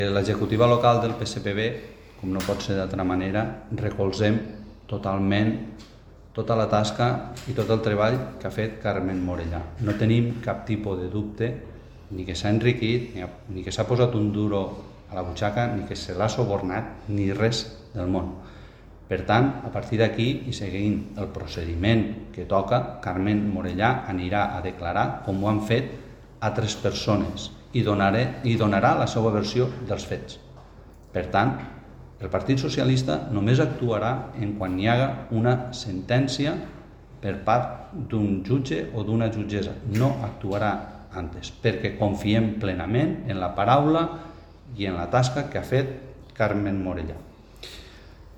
acompanyat del president del Partit i del Secretari General ha donat una roda de premsa on ha recolzat a la regidora Carmen Morellà pel cas Ferralla.  Així mateix ha demanat la col·laboració del PVI i VOX per a desbloquejar la situació en que està l’ajuntament i els ha convidat a col·laborar en la governabilitat  de l’Ajuntament de Vinaròs i que aporten les seves propostes.